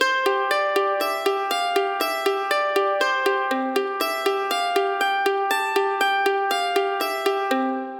逆に単一の楽器が単音をつらつらと奏でていても、一部が勝手に「伴奏」側に回ってしまうこともあります。
こちらは「ドソ レソ ミソ ファソ…」と単音を演奏しているのですが、どうも合間の「ソ」がオマケに思えて、自ずと耳が「ドレミファミレド…」という「かえるのうた」のフレーズを追ってしまうと思います。
m1-kaeru-guitar.mp3